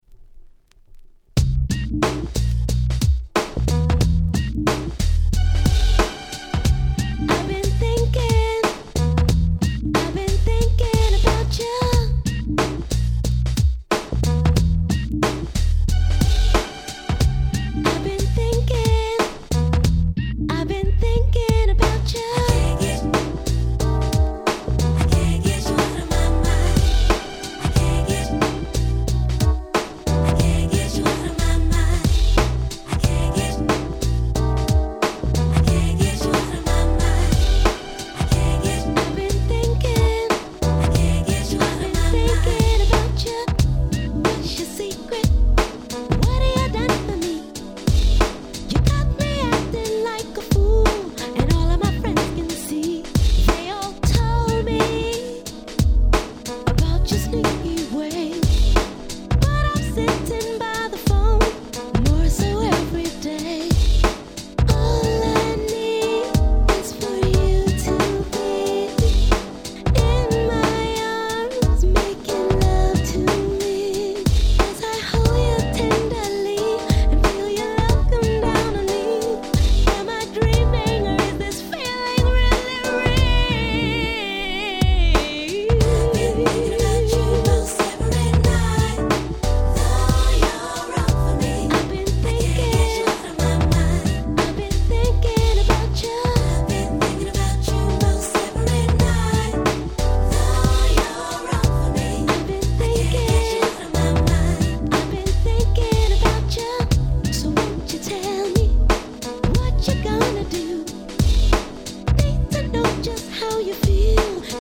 95' Nice UK R&B !!
切ないMelodyが堪りません！